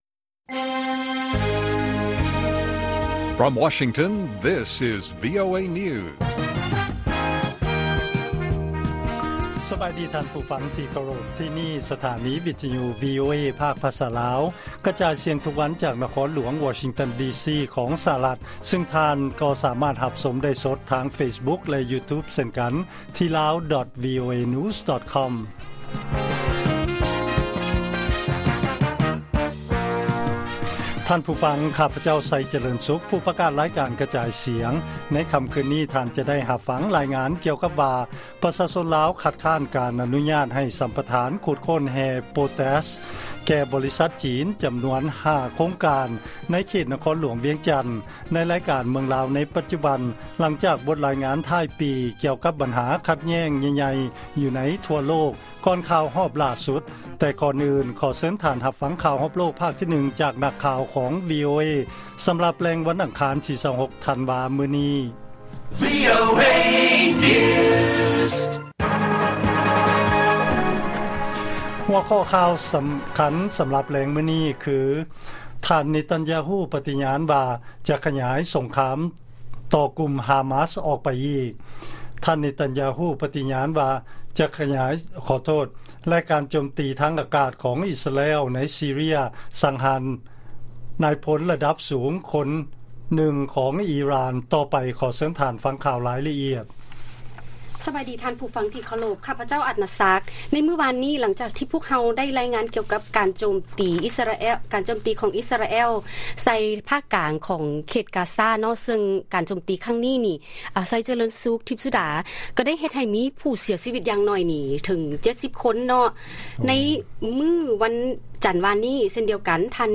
ລາຍການກະຈາຍສຽງຂອງວີໂອເອ ລາວ: ທ່ານເນຕັນຢາຮູ ປະຕິຍານວ່າ ຈະຂະຫຍາຍສົງຄາມຕໍ່ກຸ່ມຮາມາສ, ໃນຂະນະທີ່ ຕົວເລກຜູ້ເສຍຊີວິດ ໃນເຂດກາຊາ ເພີ້ມຂຶ້ນ